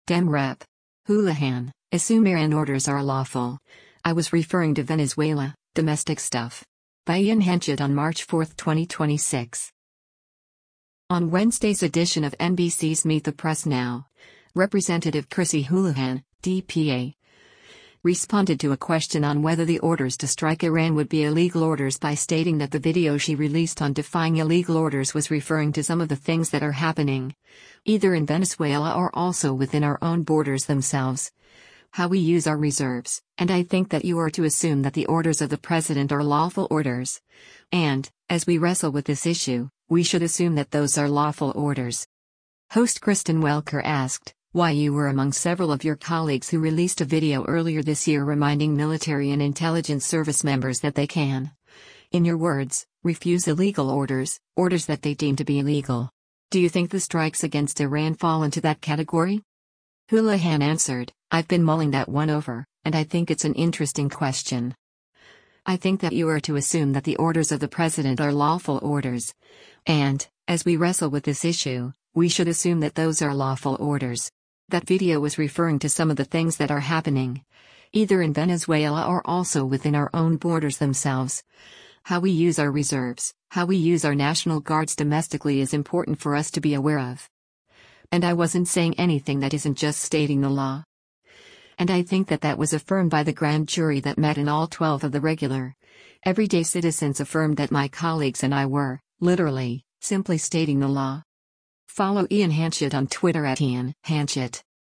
On Wednesday’s edition of NBC’s “Meet the Press NOW,” Rep. Chrissy Houlahan (D-PA) responded to a question on whether the orders to strike Iran would be illegal orders by stating that the video she released on defying illegal orders “was referring to some of the things that are happening, either in Venezuela or also within our own borders themselves, how we use our reserves,” and “I think that you are to assume that the orders of the president are lawful orders, and, as we wrestle with this issue, we should assume that those are lawful orders.”